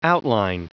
Prononciation du mot outline en anglais (fichier audio)
Prononciation du mot : outline